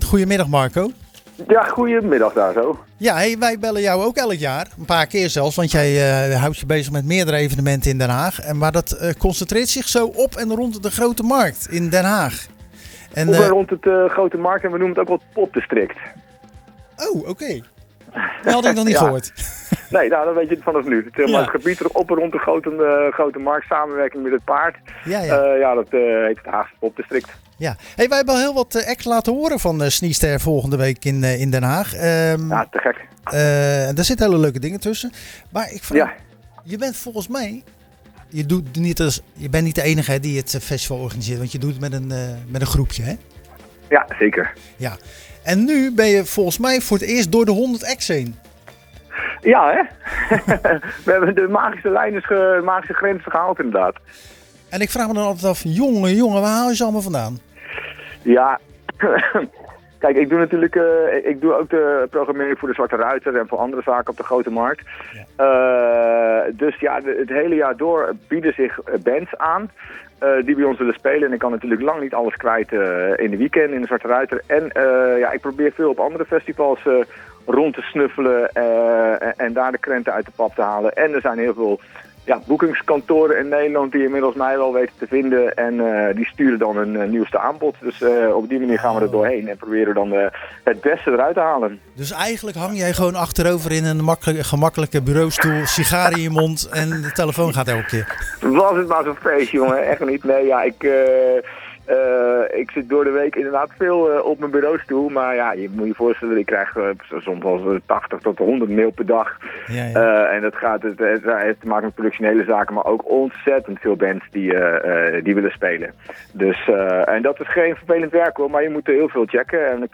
Tijdens de wekelijkse editie van Zwaardvis belde we ��n van de programmeurs van het Haagse Sniester Festival.�